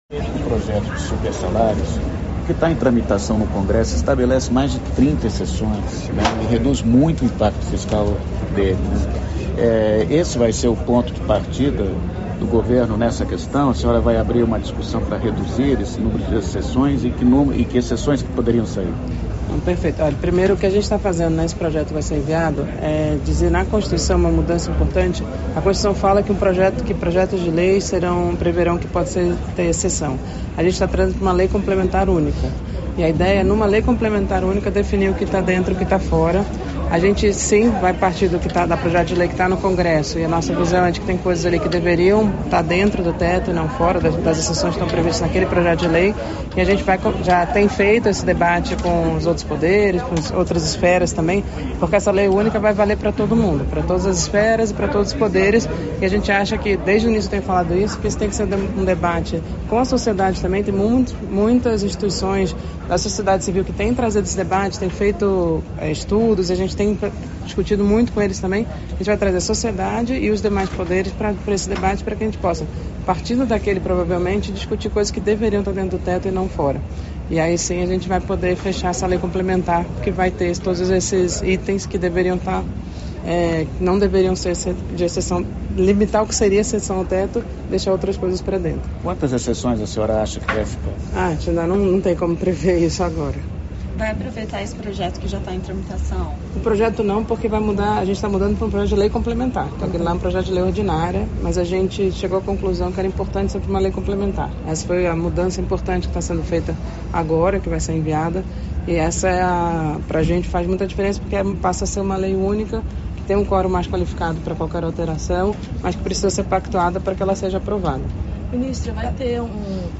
Íntegra da entrevista concedida pela ministra da Gestão e Inovação em Serviços Públicos (MGI), Esther Dweck, nesta quinta-feira (28), após a participação no Congresso Internacional do Centro Latino-americano de Administração para o Desenvolvimento, em Brasília.